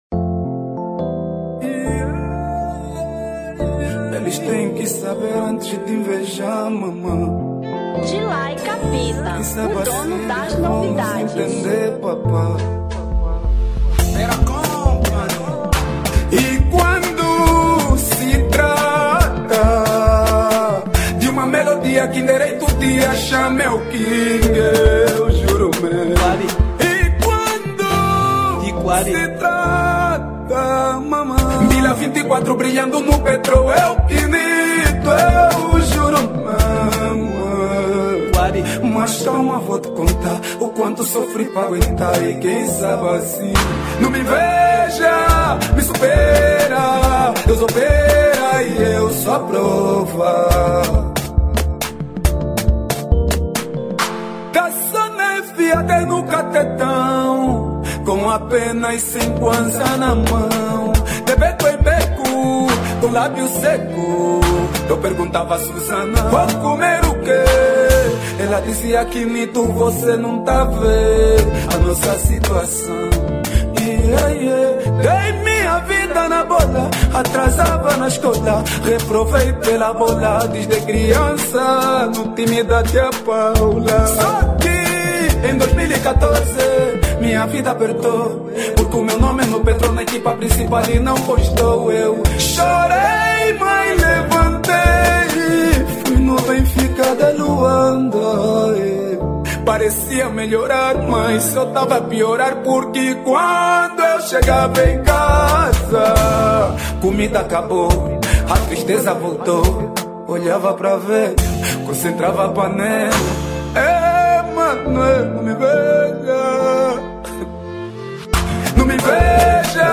Kuduro 2022